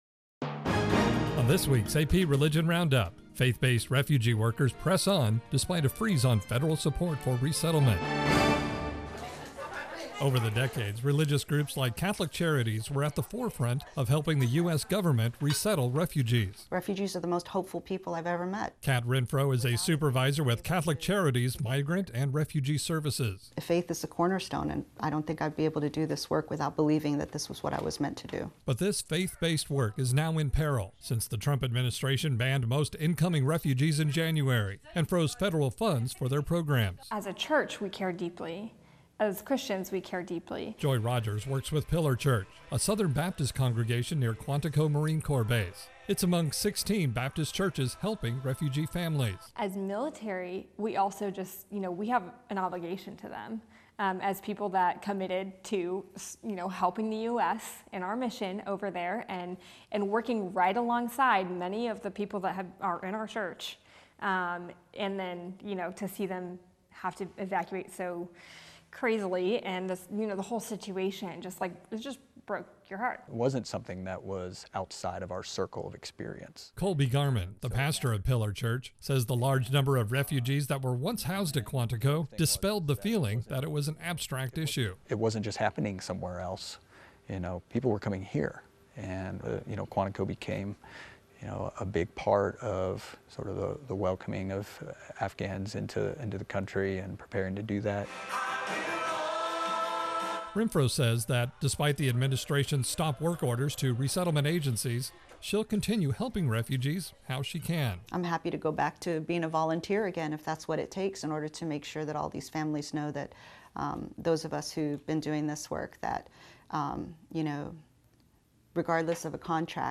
On this week's AP Religion Roundup, faith-based resettlement workers press on despite a freeze on federal support for refugees. AP Correspondent